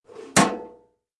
mailbox_close_1.ogg